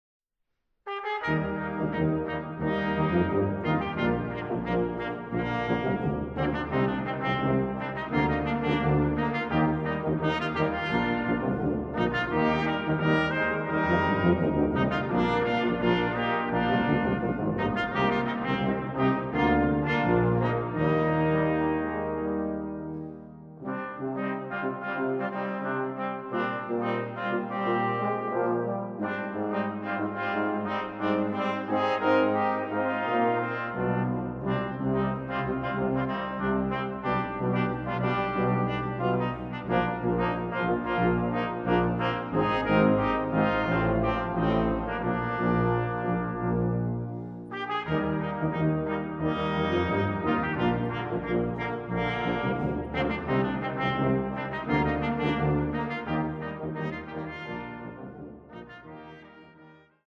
Musik für Posaunenchor und Blechbläserensemble